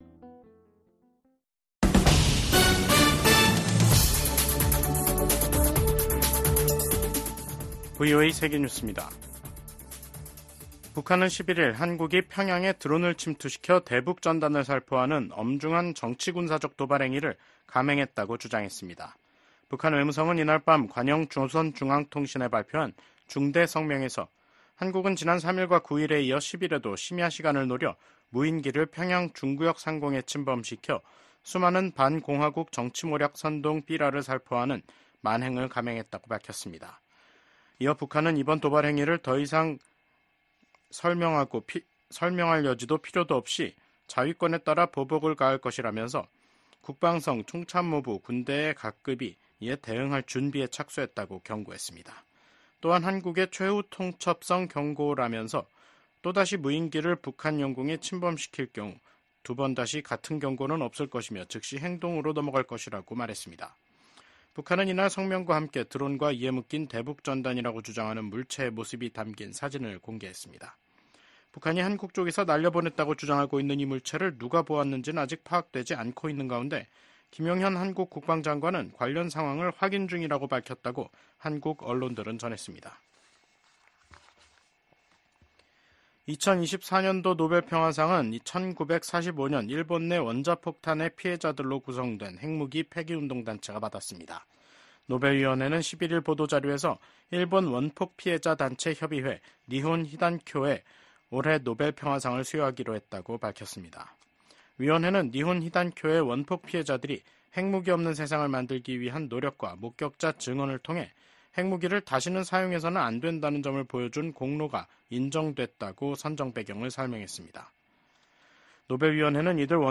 VOA 한국어 간판 뉴스 프로그램 '뉴스 투데이', 2024년 10월 11일 3부 방송입니다. 한국 작가로는 최초로 소설가 한강 씨가 노벨문학상 수상자로 선정됐습니다. 윤석열 한국 대통령은 동아시아 정상회의에서 북한과 러시아의 불법적 군사 협력을 정면으로 비판했습니다.